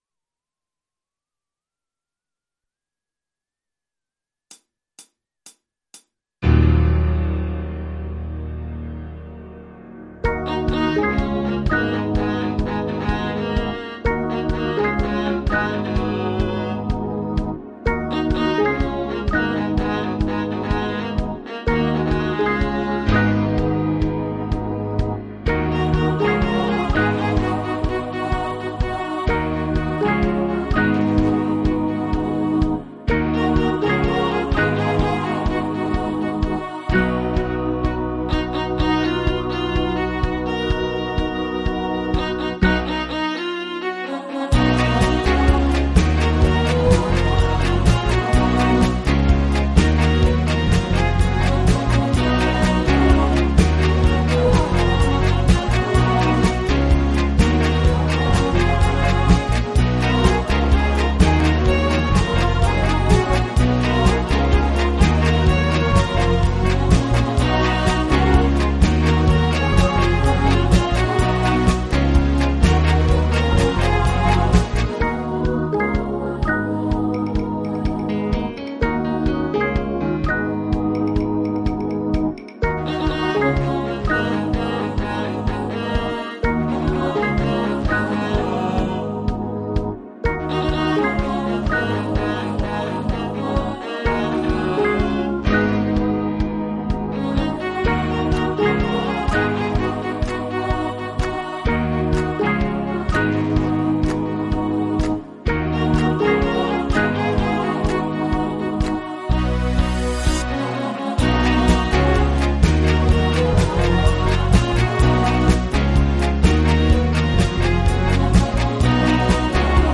versione strumentale multitraccia